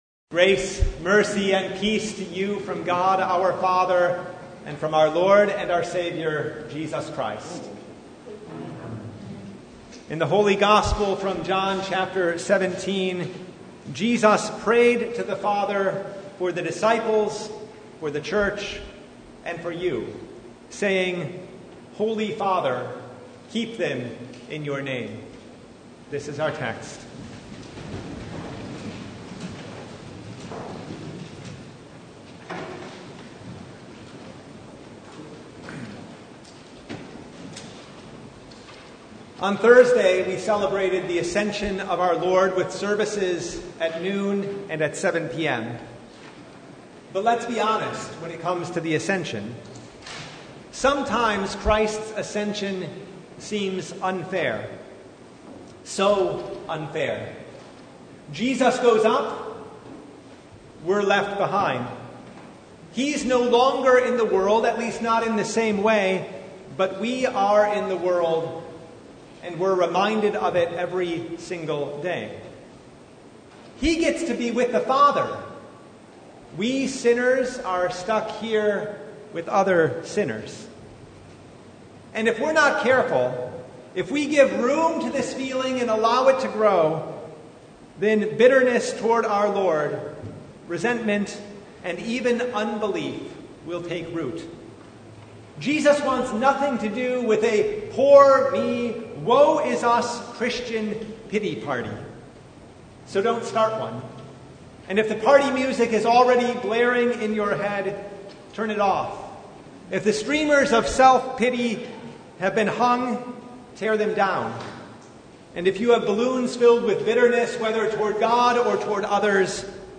Service Type: Sunday
Sermon Only « The Seventh Sunday of Easter